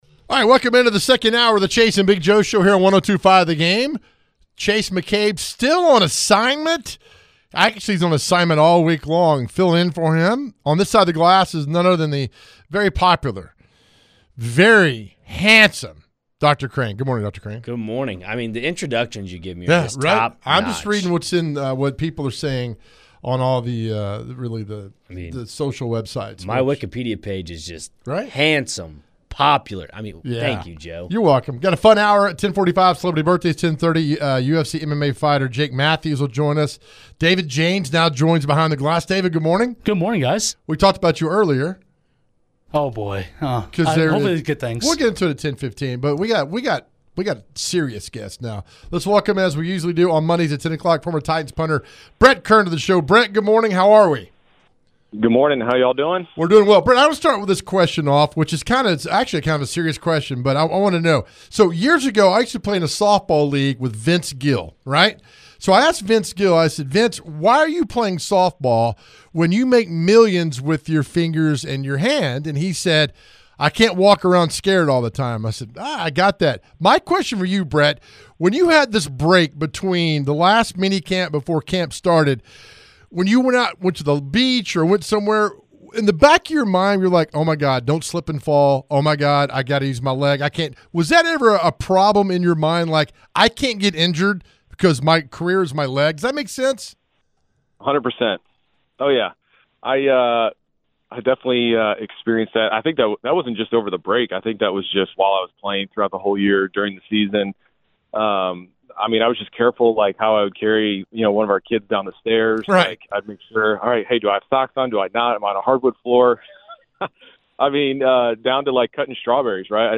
In the second hour, Brett Kern joins the show to give his insights into the offseason and his thoughts on Nashville SC. Jake Matthews, a UFC Fighter, joins the show to preview his upcoming fight in Nashville on July 12. The hour ends with celebrity birthdays.